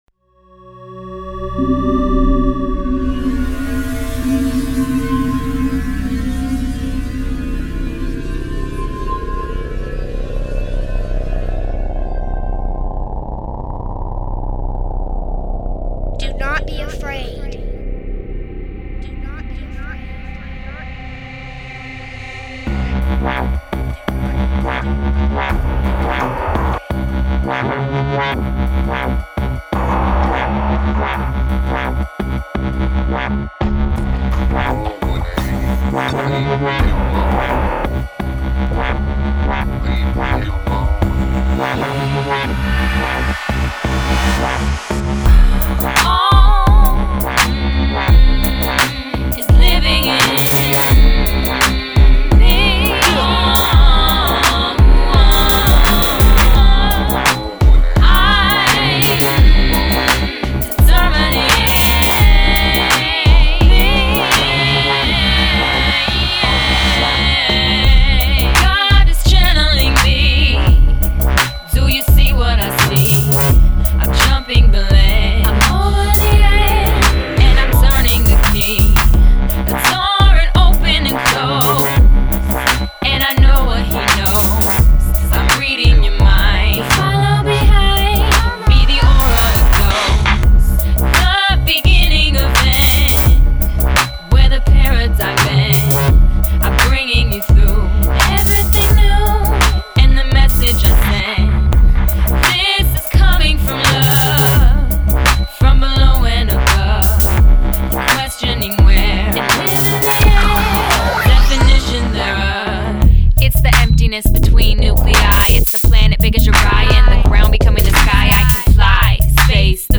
Recorded at Ground Zero Studios